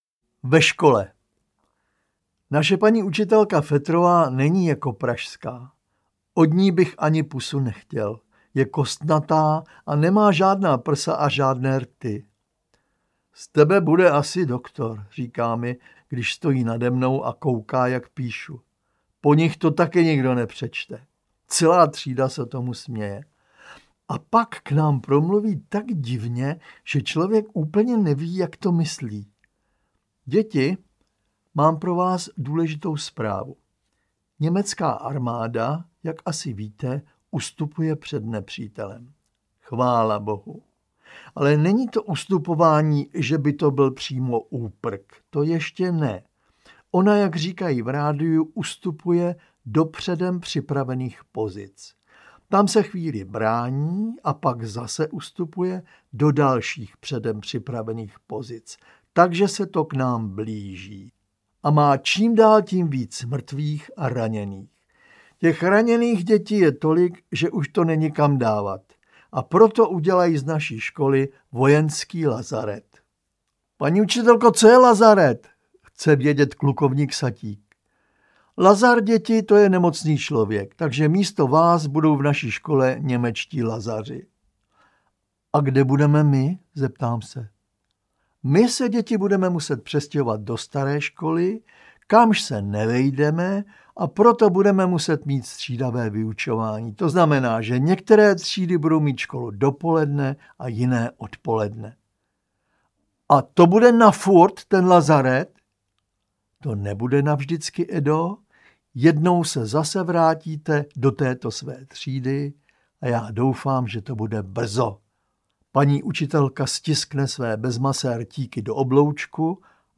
Po strništi bos audiokniha
Ukázka z knihy
• InterpretZdeněk Svěrák